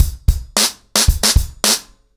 BlackMail-110BPM.15.wav